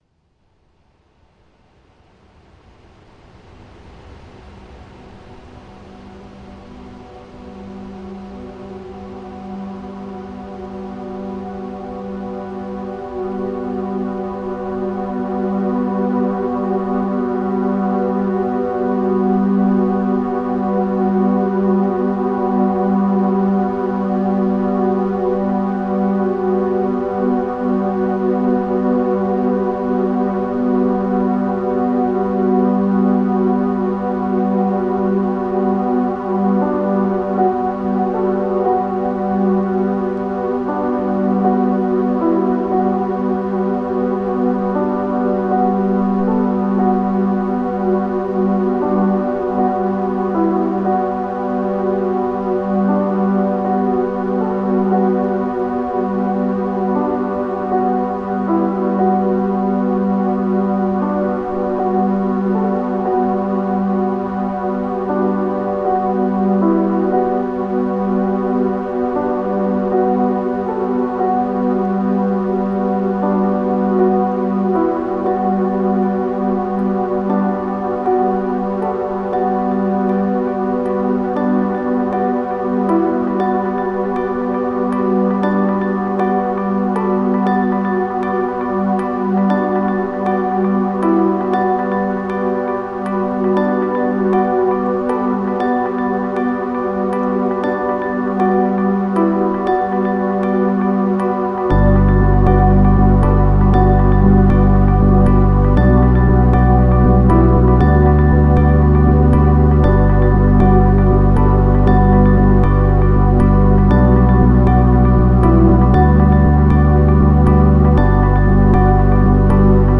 Genre: Ambient/Deep Techno/Dub Techno/Electro.